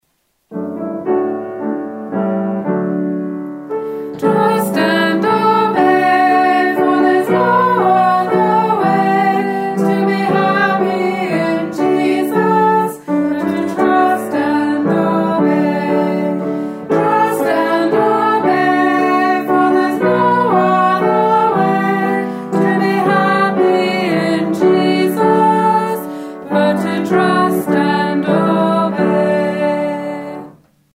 Singing of Sunday School Choruses